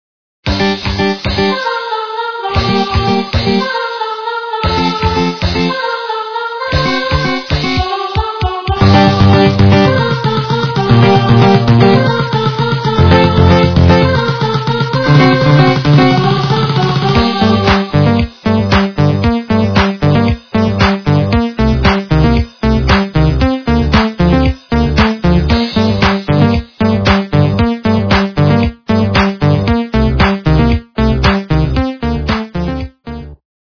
- русская эстрада
ремикс качество понижено и присутствуют гудки.